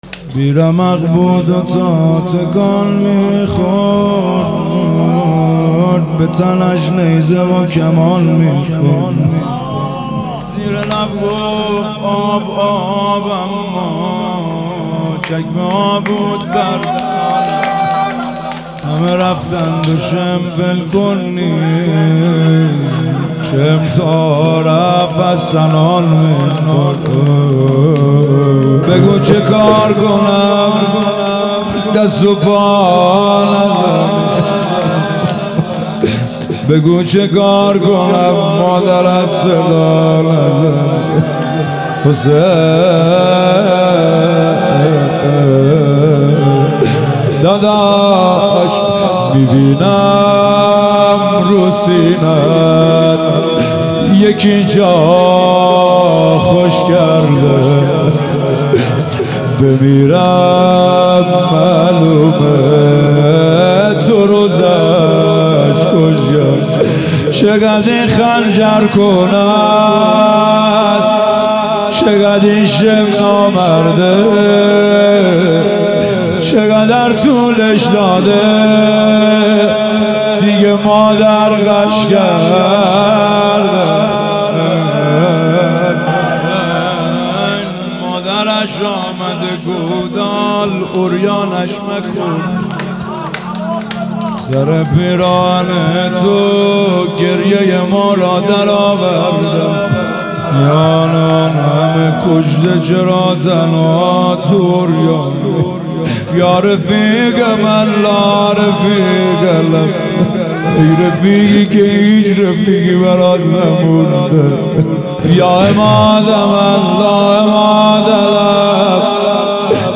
محرم 1399 | هیئت عشاق الرضا (ع) تهران